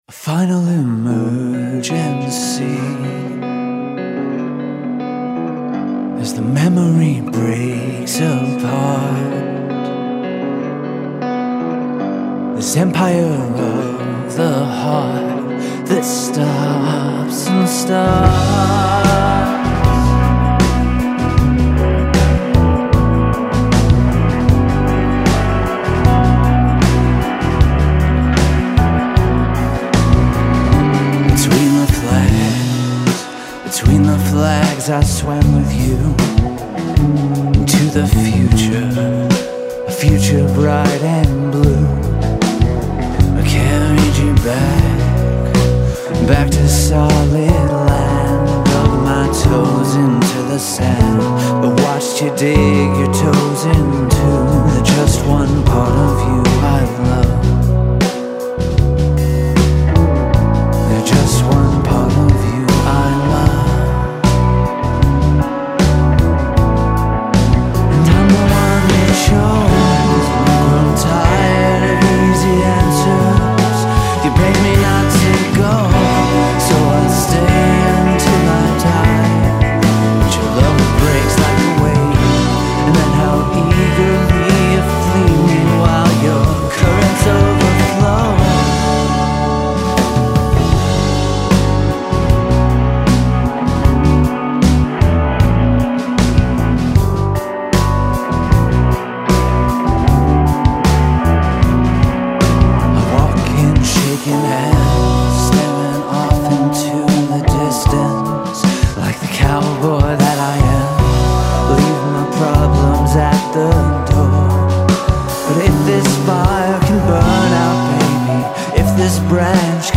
DRUMS, PERCUSSION
BASS
GUITAR, KEYBOARDS, VOCALS